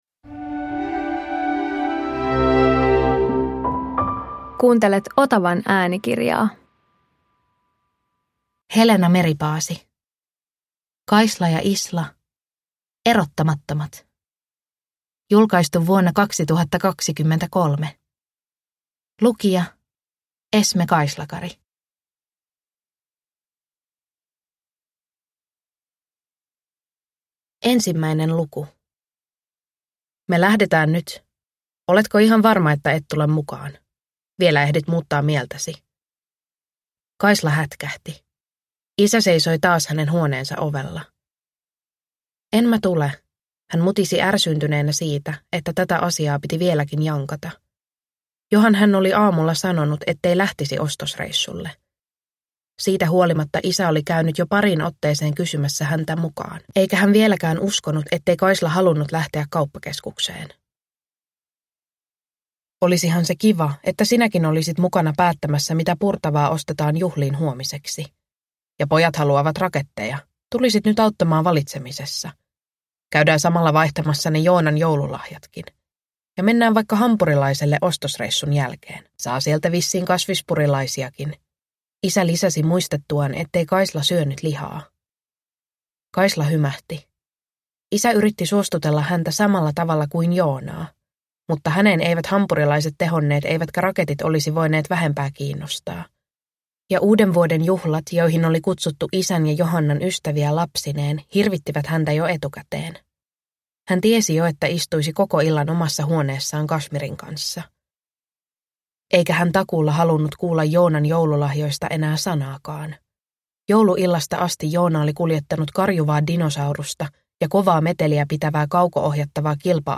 Kaisla ja Isla - Erottamattomat – Ljudbok